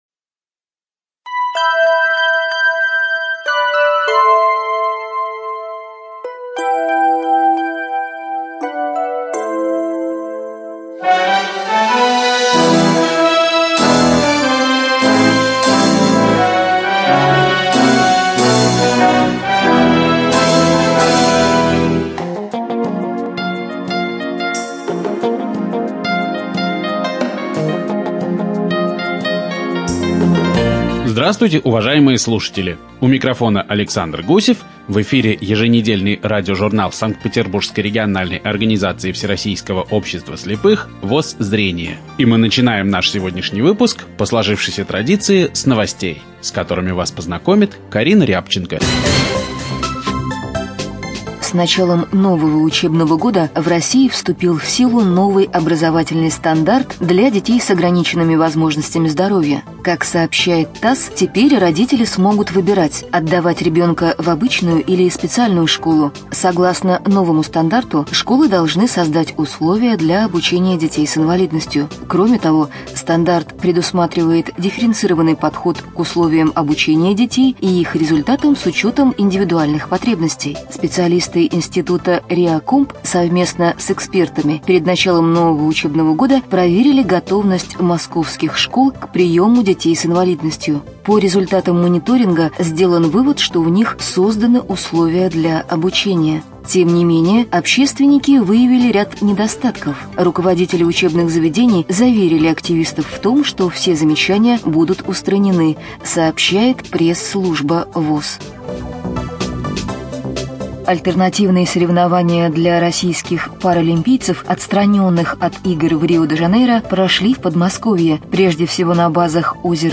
ЖанрРеабилитация, Радиопрограммы